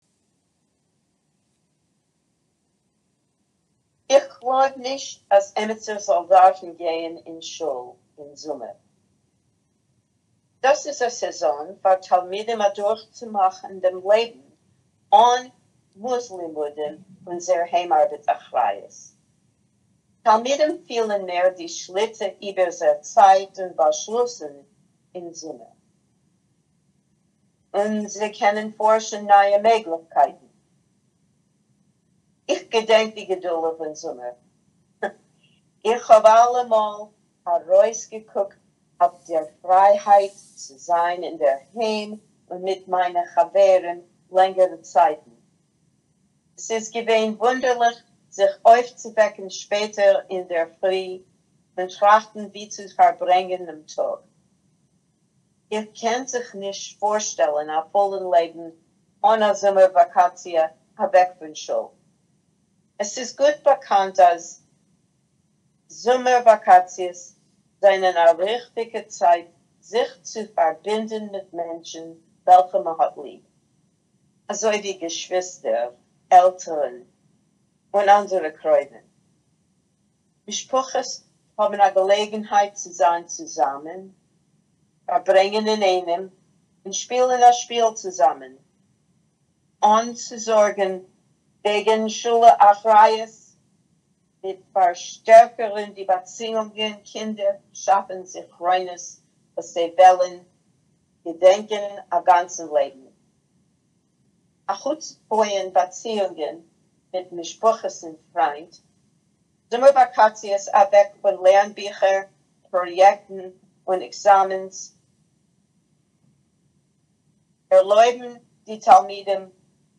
[Note: In the transcript below, two dots indicate that the speaker paused.